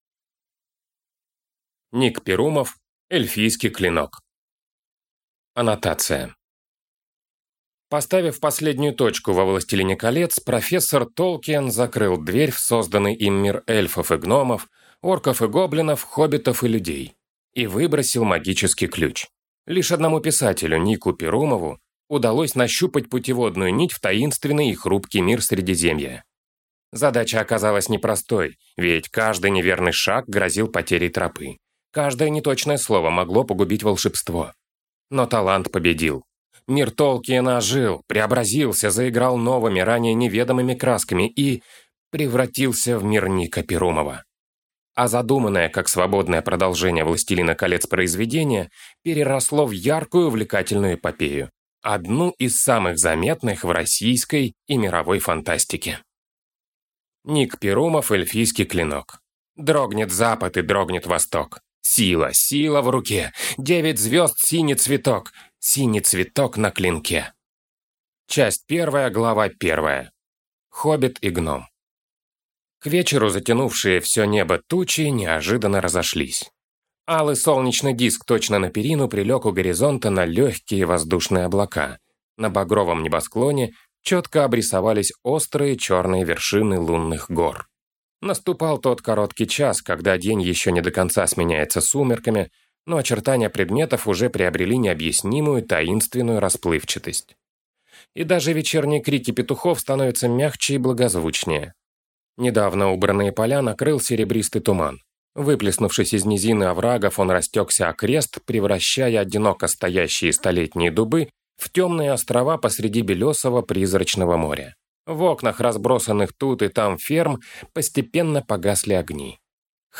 Аудиокнига Эльфийский клинок | Библиотека аудиокниг